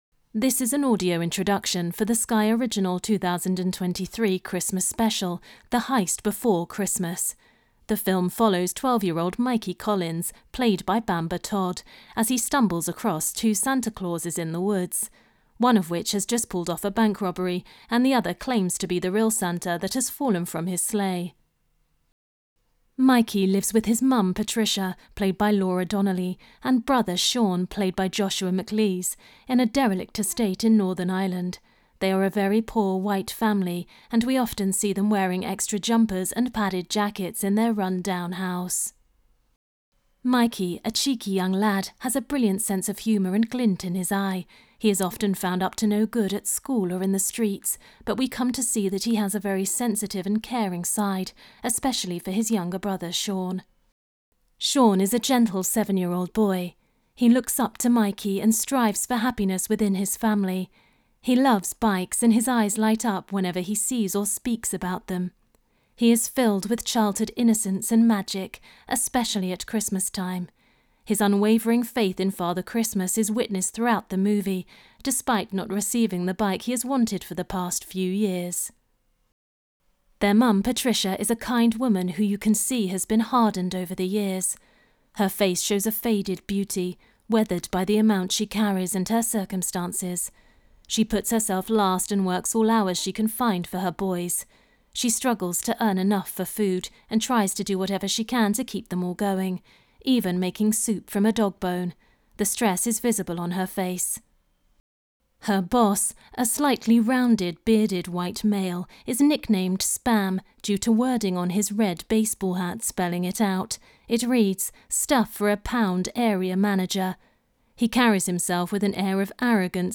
The Heist Before Christmas - AD Intro